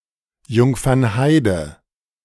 Jungfernheide (German pronunciation: [ˌjʊŋfɐnˈhaɪ̯də]
De-Jungfernheide.ogg.mp3